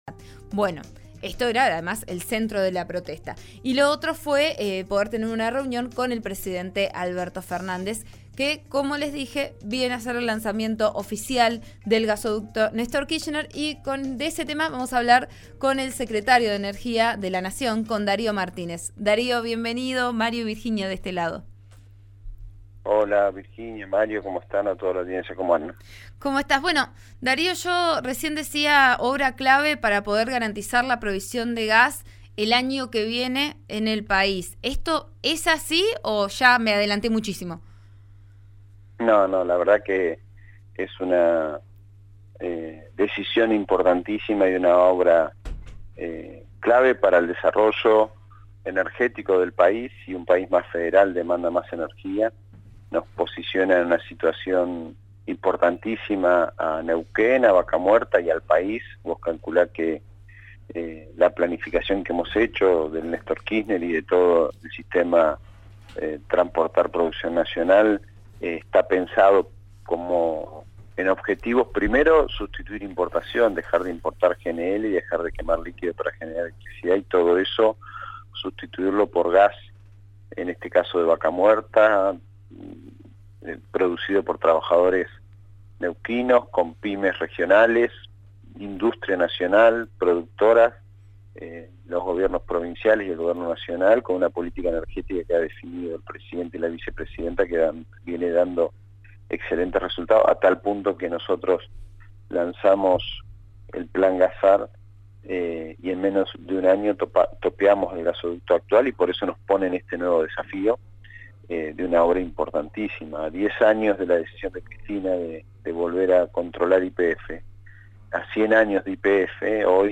Durante la mañana, Martínez dialogó con Vos A Diario por RN Radio y explicó su postura.